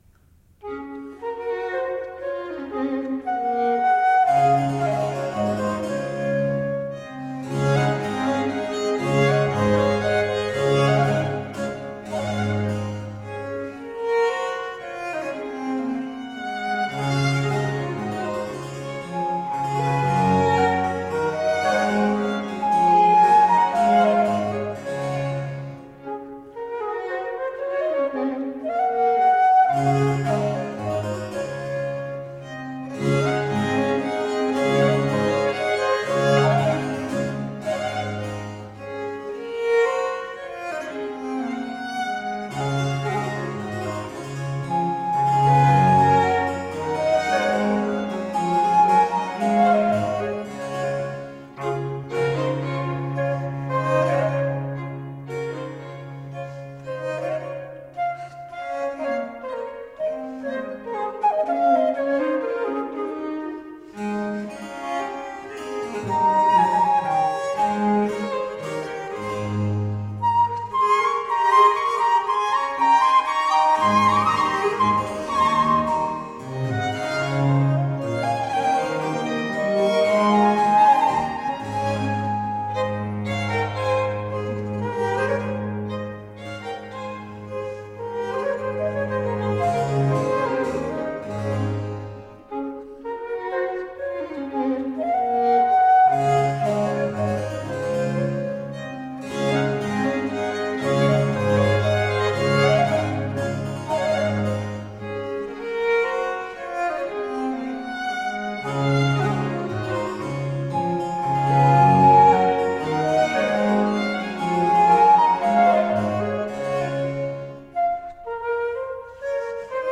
Rare and extraordinary music of the baroque.
lightly elegant dance music
violinist
flutist
harpsichord
violoncello